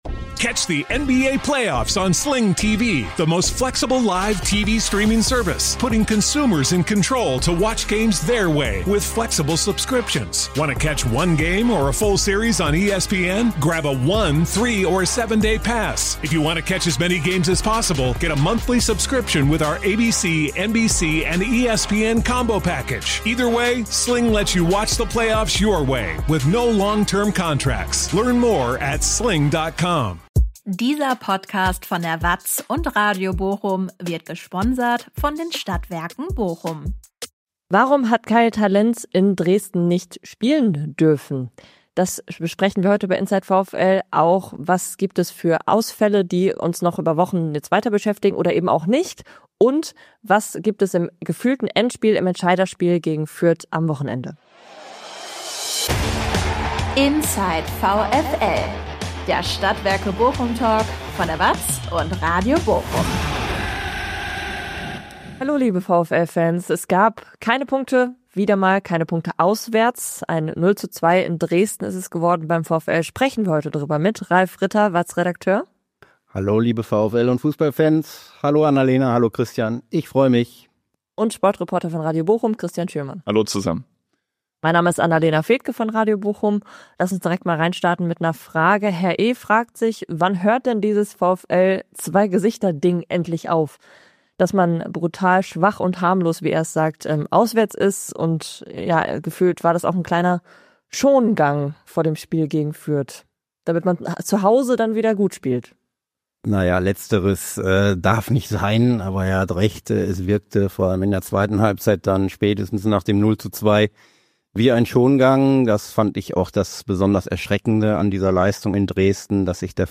Thema im Talk.